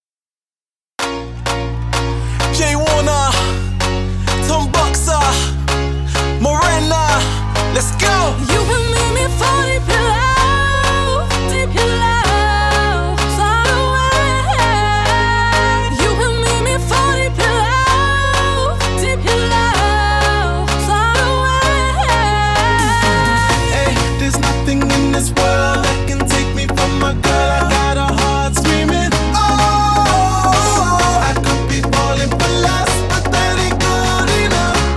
Dance Electronic
Жанр: Танцевальные / Электроника